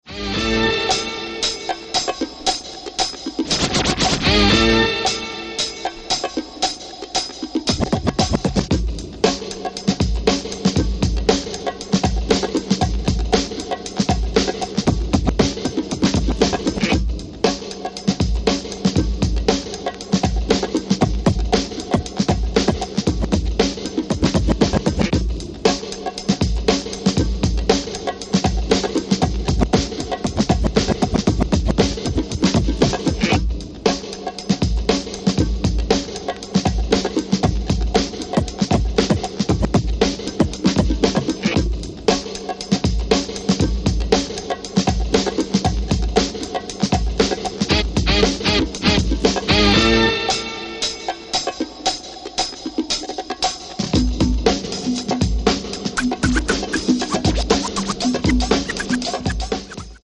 Classic bongo breakdance mayhem